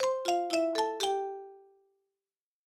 Звуки фейсбука
Notification 9